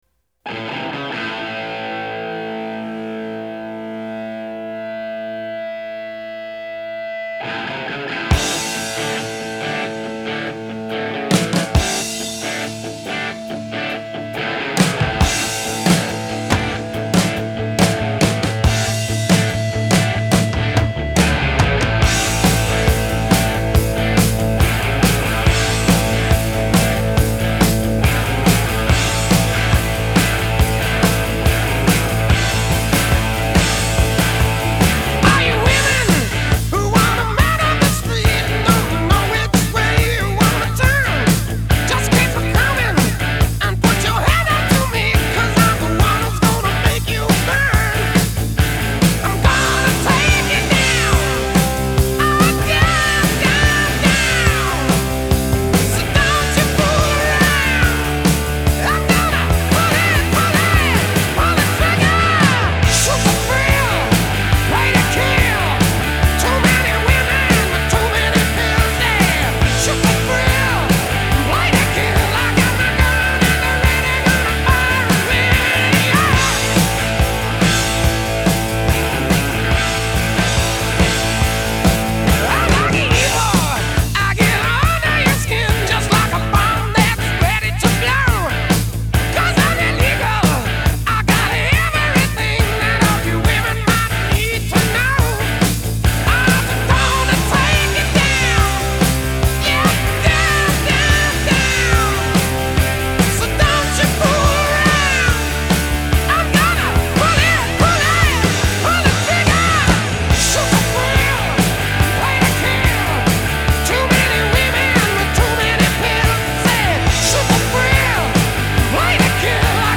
Жанр: Hard Rock, Heavy Metal